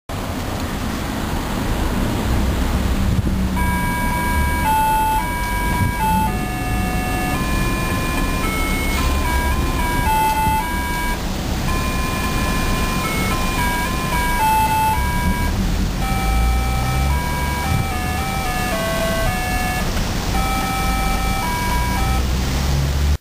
テンポは□号ですが音質は２１号のように聞こえます。
これは随分ゆっくりリズムですね！